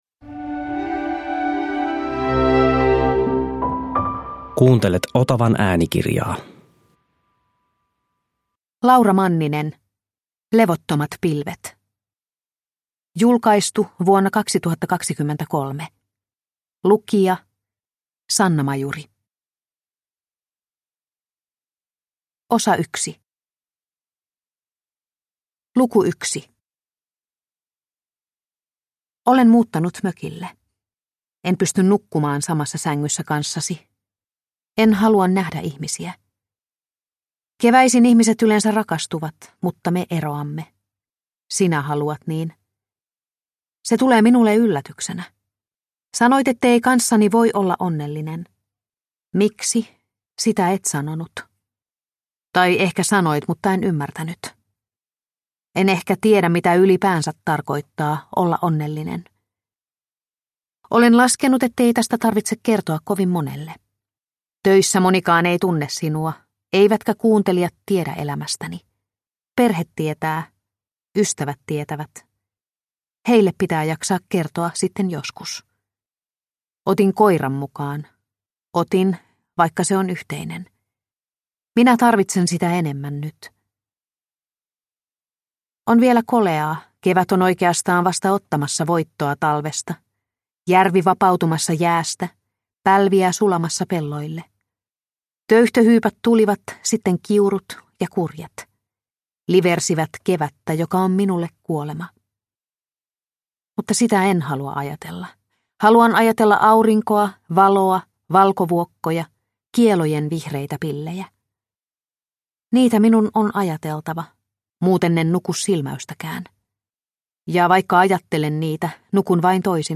Levottomat pilvet – Ljudbok – Laddas ner